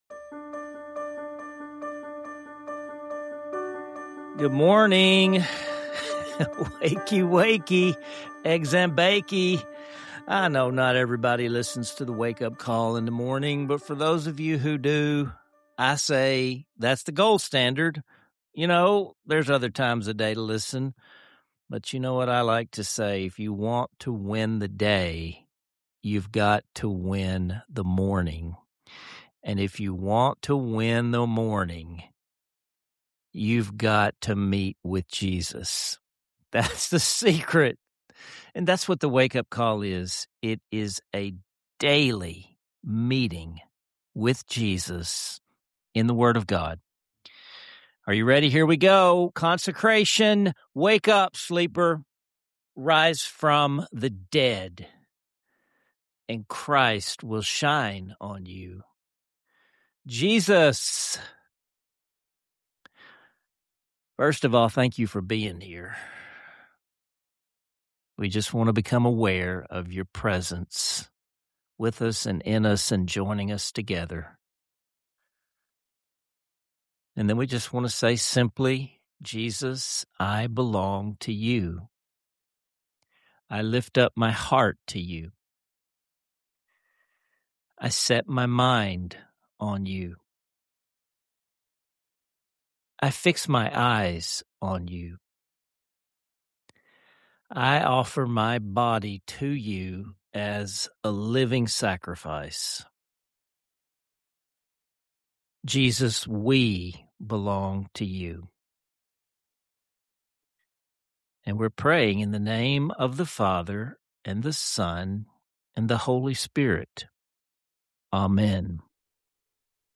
Stay tuned until the end for a beloved hymn that will help “wake up” your heart to the power and presence of divine love.